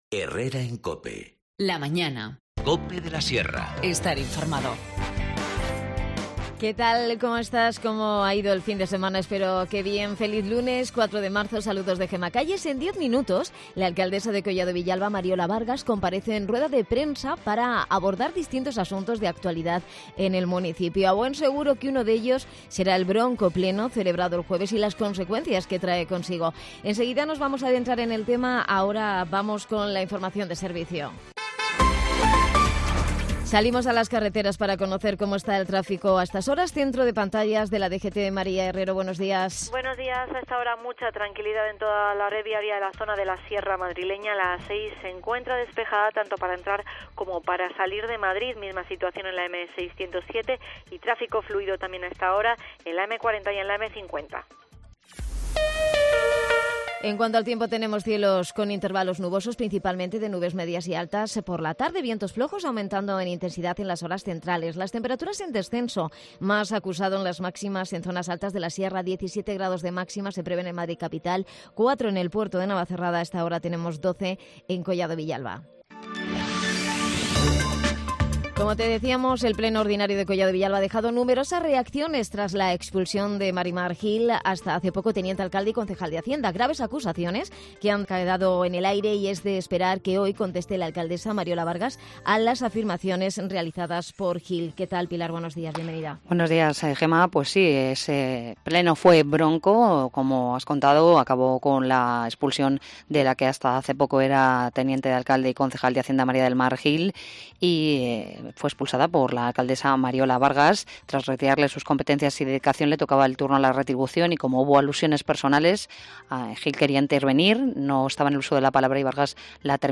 Guiomar Romero, concejal de Medioambente nos explica todos los detalles.